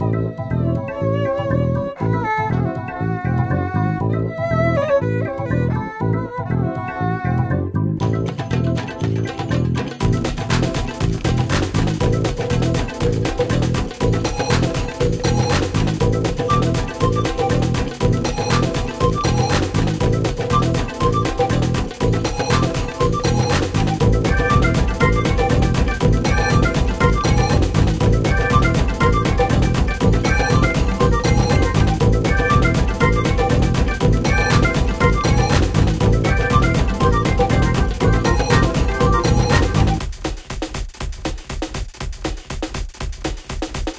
ダンス音楽系のノリですから、そういうのが好きではない人は避けた方が良いと思います。